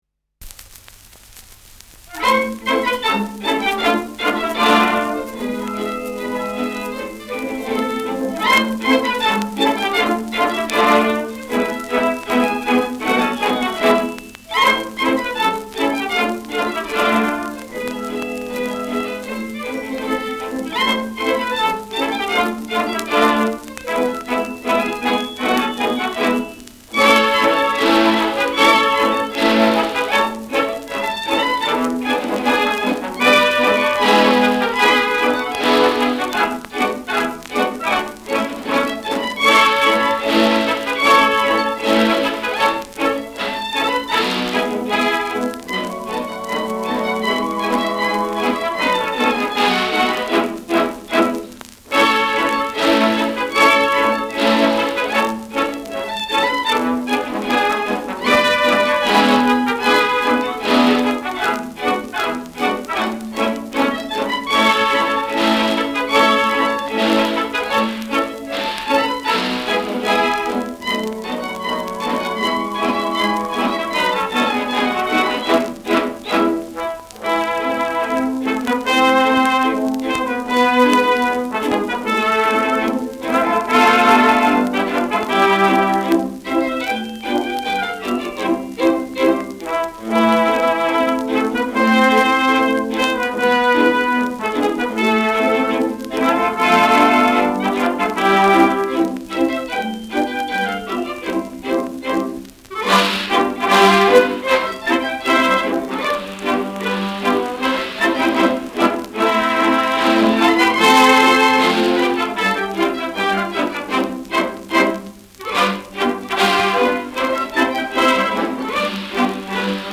Schellackplatte
leichtes Rauschen : leichtes Knistern
Schlagwerk mit Glockenspiel.
[Berlin] (Aufnahmeort)